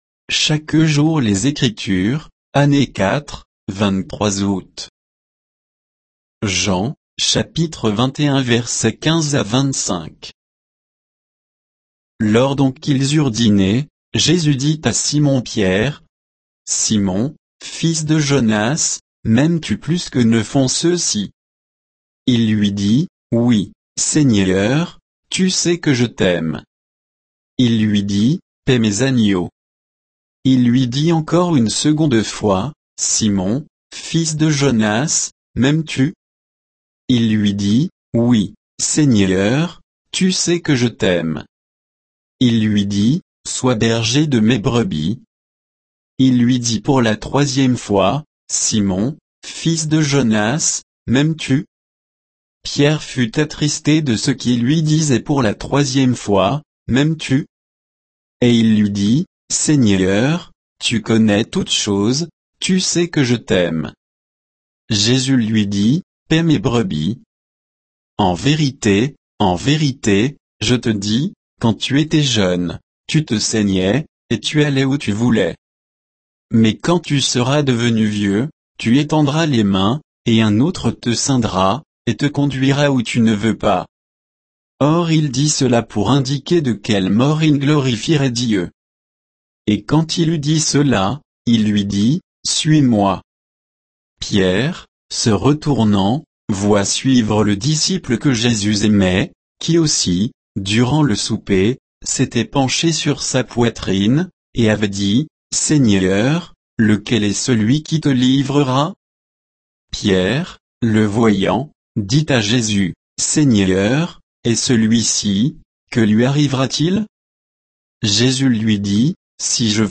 Méditation quoditienne de Chaque jour les Écritures sur Jean 21, 15 à 25